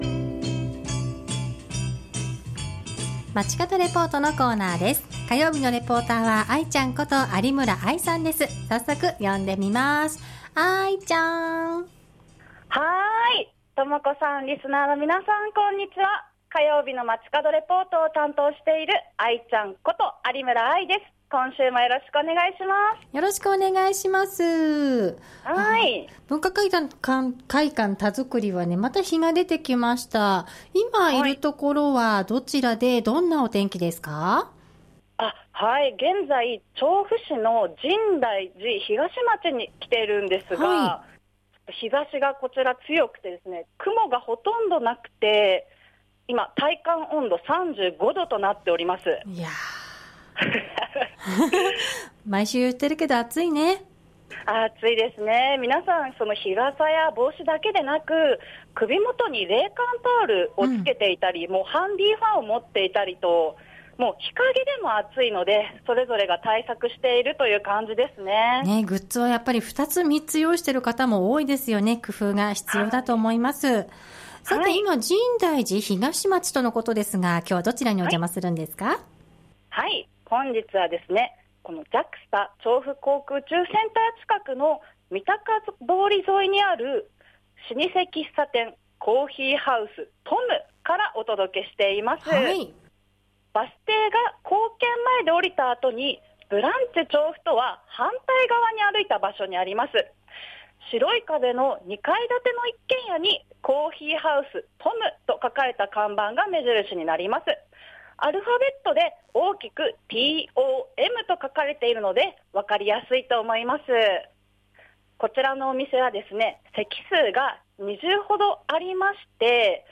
本日は深大寺東町8丁目にある「コーヒーハウスTOM」からお届けしました。